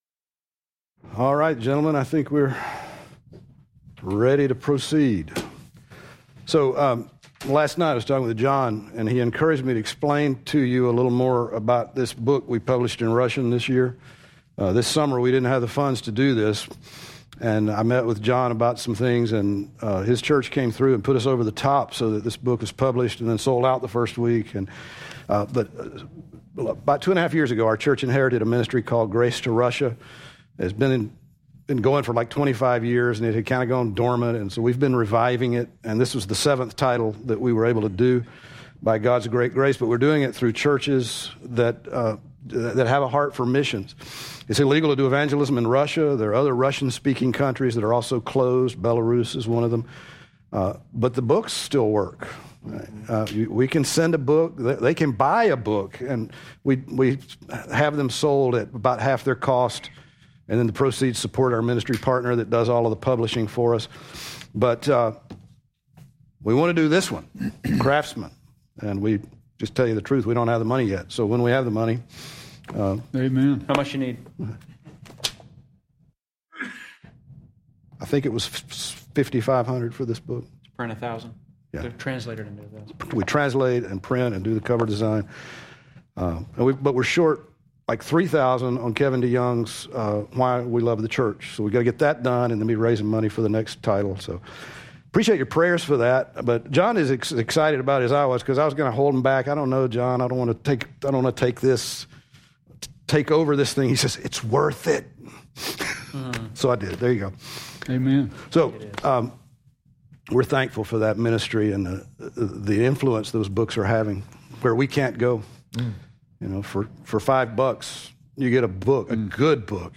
Session 5 Q&A - Panel